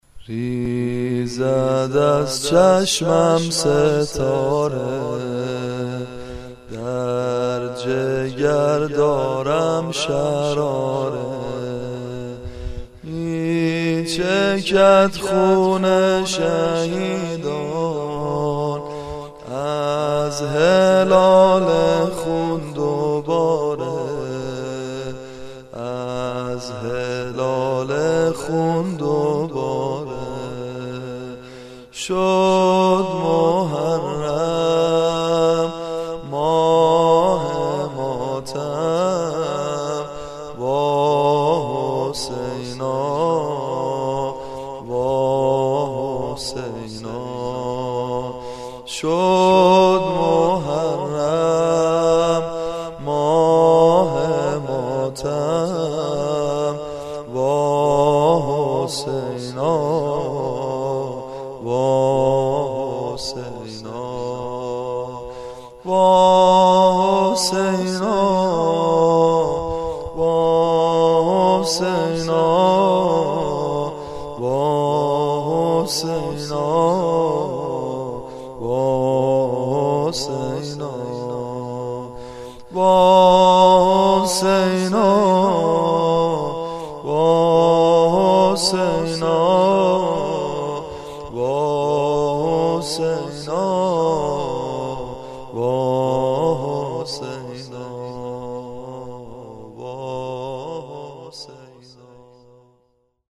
در استودیوی عقیق